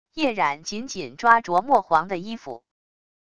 夜染紧紧抓着墨皇的衣服wav音频生成系统WAV Audio Player